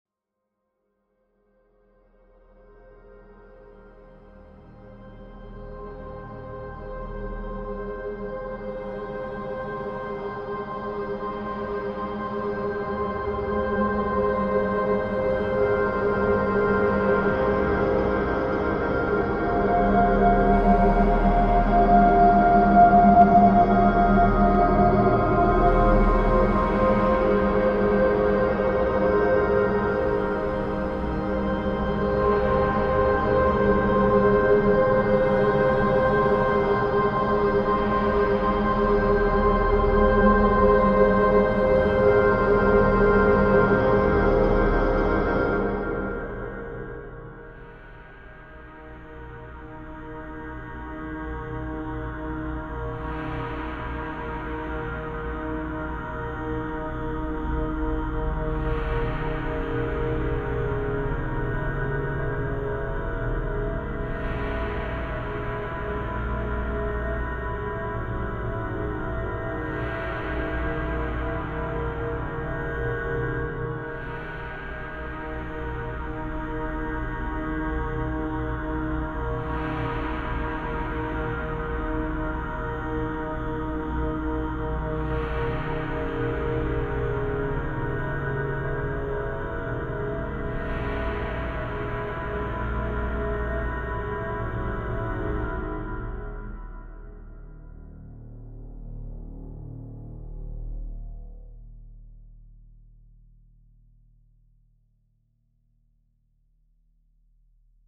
Extraits de la bande son de la performance de danse